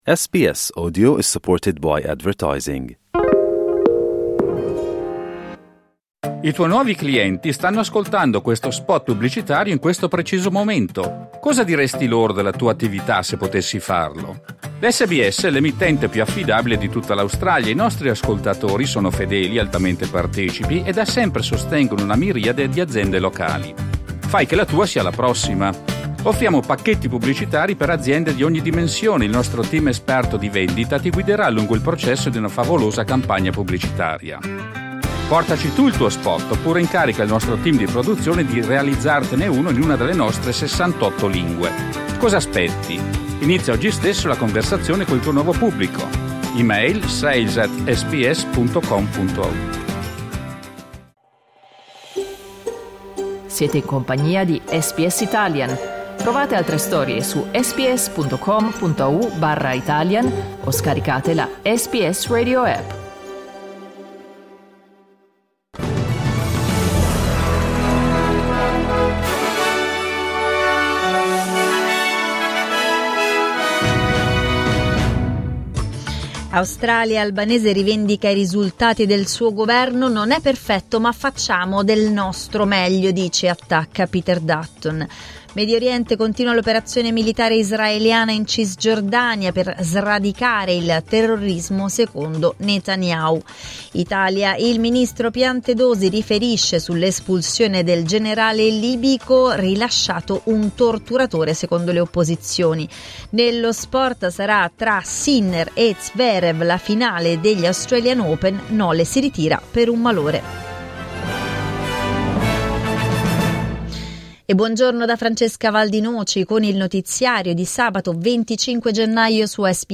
… continue reading 1 Giornale radio sabato 25 gennaio 2025 14:01 Play Pause 7h ago 14:01 Play Pause נגן מאוחר יותר נגן מאוחר יותר רשימות לייק אהבתי 14:01 Il notiziario di SBS in italiano.